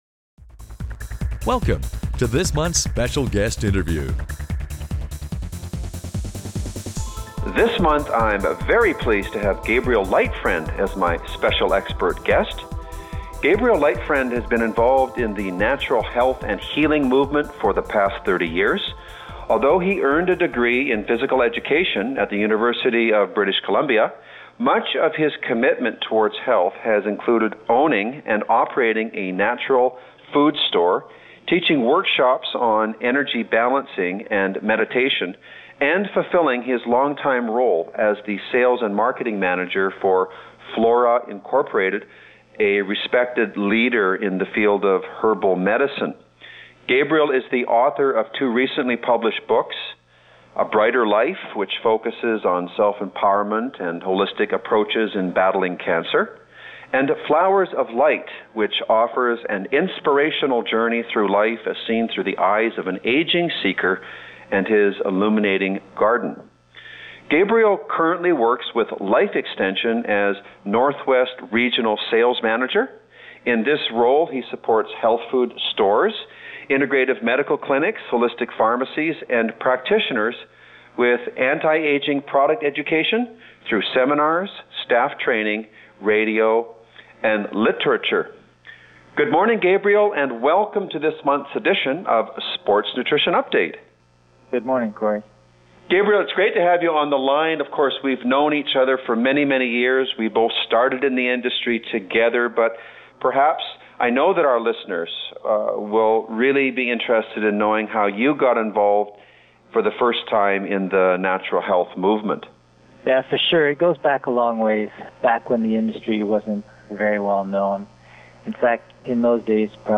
Special Guest Interview Volume 8 Number 2 V8N2c